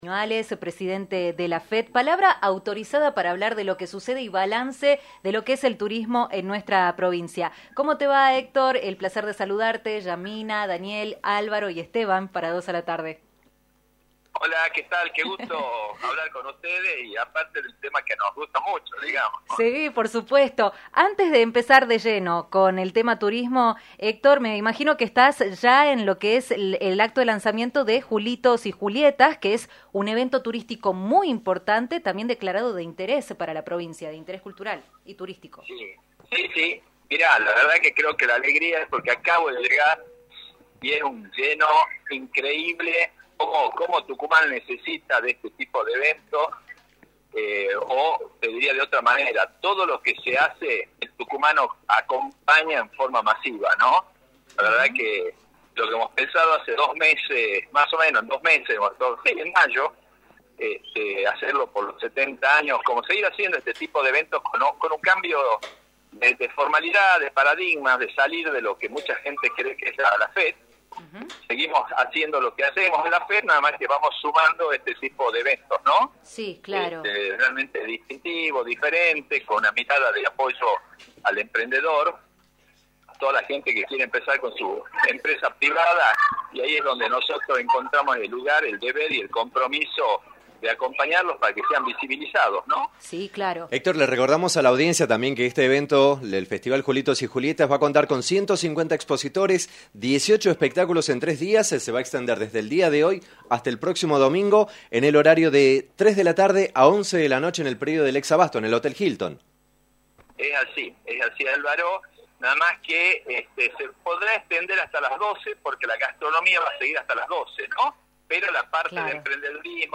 conversó con el equipo de   «Dos a la Tarde»  por la  Rock&Pop FM 106.9, en relación al impacto de la temporada turístico de invierno en la provincia.